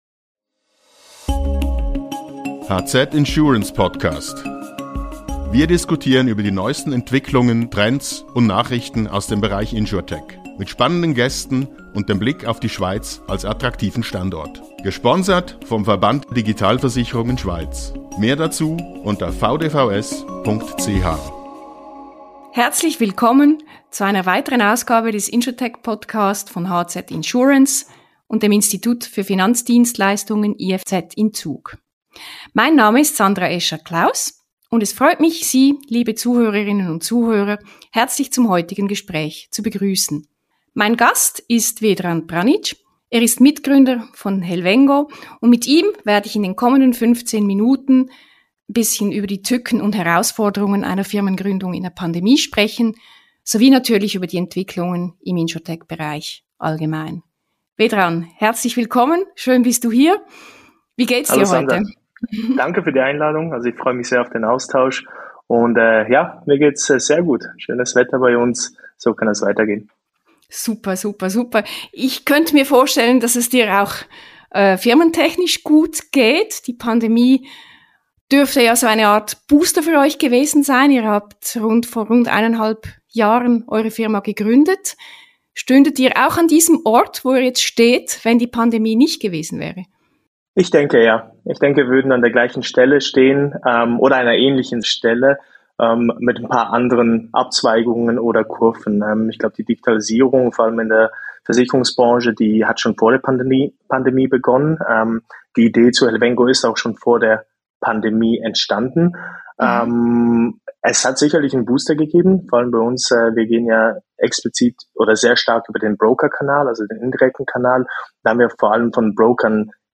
Im Gespräch erzählt er, welche Booster-Wirkung die Pandemie auf die Broker hatte und warum Helvengo in Sachen Dataspezialistinnen und -spezialisten auf eine konsequente Nearshoring-Strategie setzt.